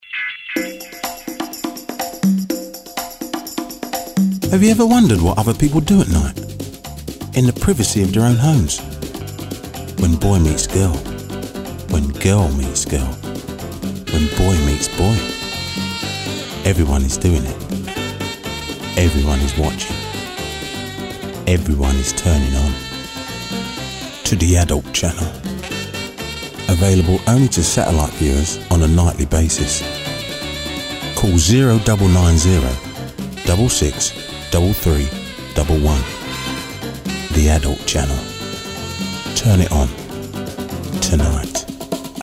Commercial, Deep, Sexy, Conversational, Smooth
London, Straight